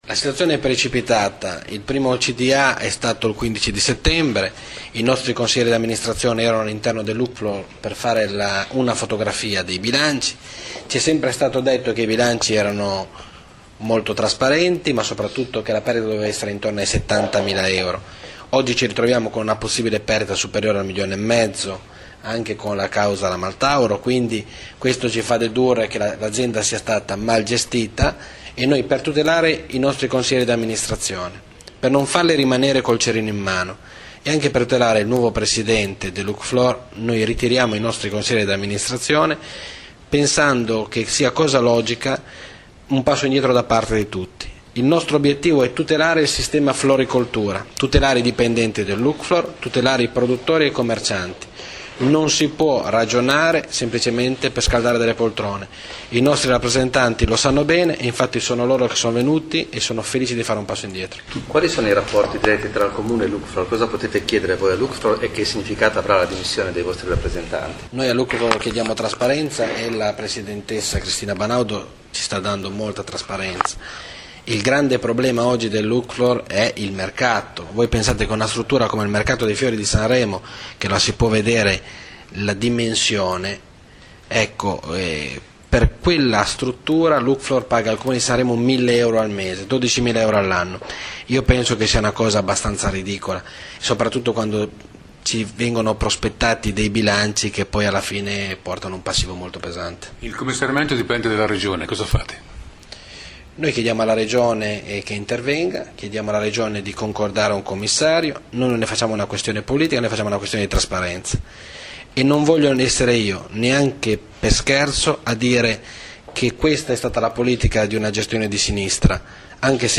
In un momento particolarmente difficile dell’azienda, il Sindaco è stato molto duro nel suo monito (l'audio dell'intervista cliccando